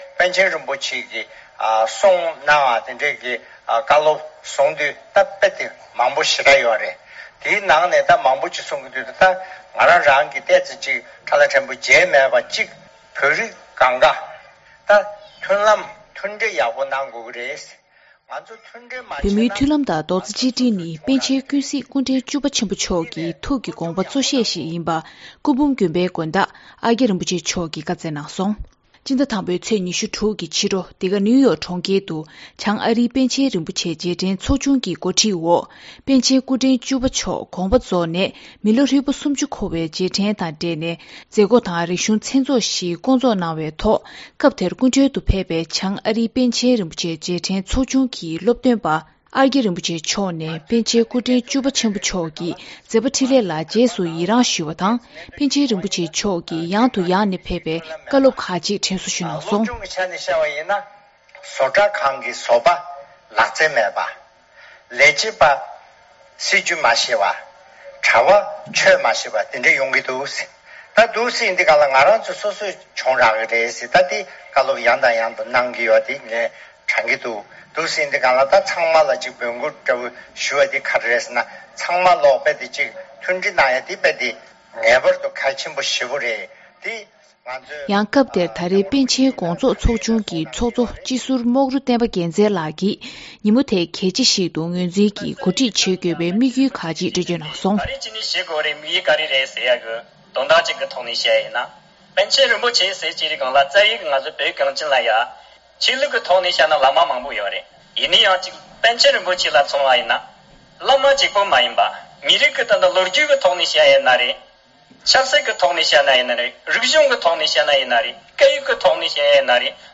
ནིའུ་ཡོག་ཏུ་༧པཎ་ཆེན་རིན་པོ་ཆེ་མཆོག་སྐུ་གཤེགས་ནས་ལོ་ངོ་༣༠འཁོར་བའི་རྗེས་དྲན་སྲུང་བརྩི་ཞུས་པ། དུས་དྲན་མཛད་སྒོ།